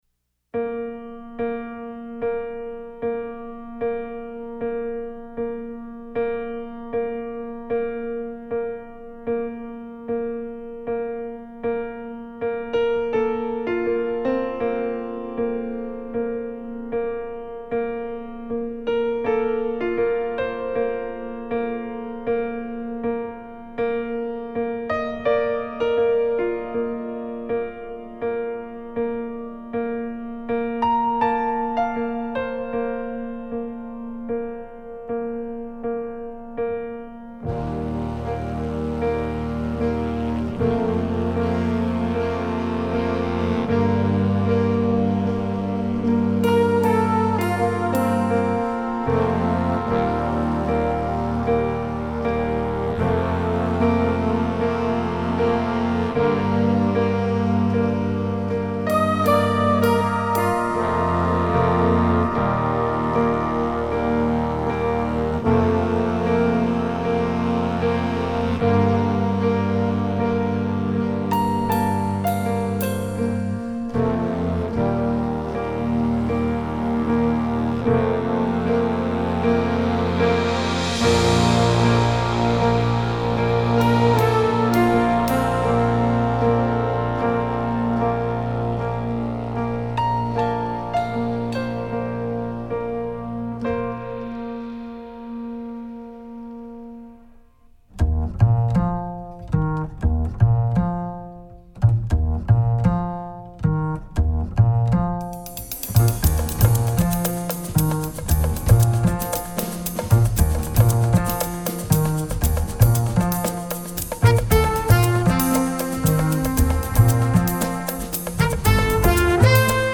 jazz, latin